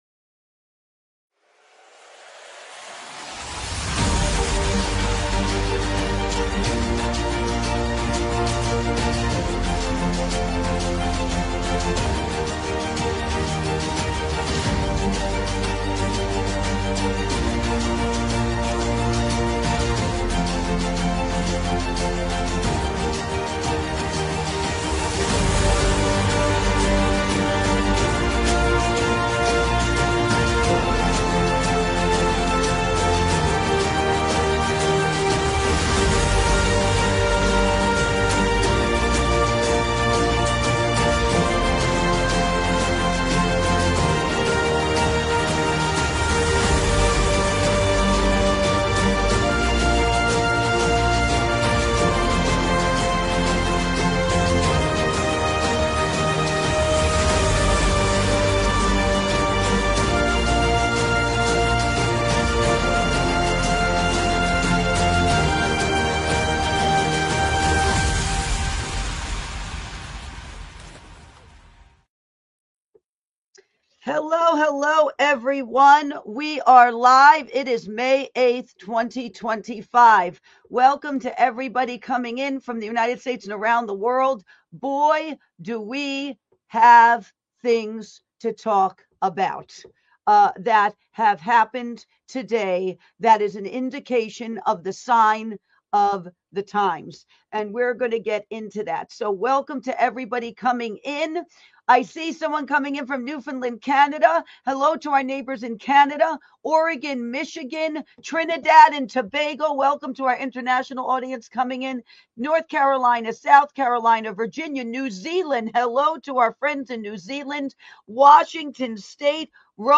Talk Show Episode
Live May 8th, 2025 at 5pm ET!